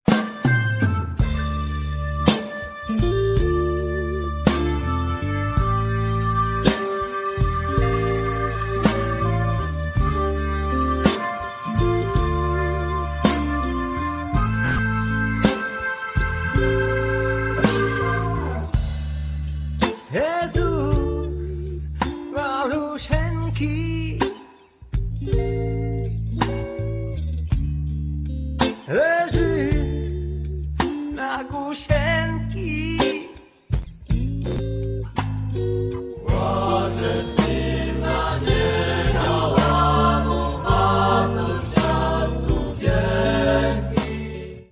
Folkrockowe kolędy po polsku i ukraińsku
gitary, bandura
perkusja
organy Hammonda
akordeon
skrzypce
cymbały
puzon
trąbka
saksofony